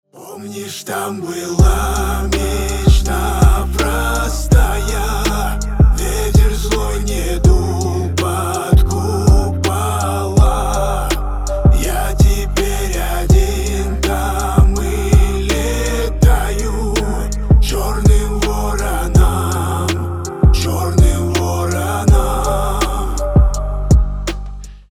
• Качество: 320, Stereo
мужской голос
лирика
грустные